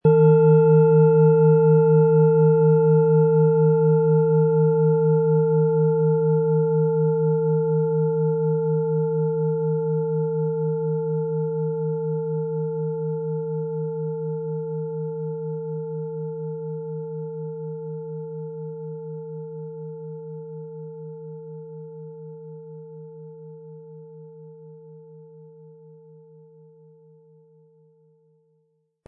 Planetenschale® Tief in Entspannung fallen & Fröhlich fühlen mit Thetawellen & Eros, Ø 21,5 cm, 1400-1500 Gramm inkl. Klöppel
• Tiefster Ton: Eros
Der Schlegel lässt die Schale harmonisch und angenehm tönen.
PlanetentöneThetawelle & Eros
MaterialBronze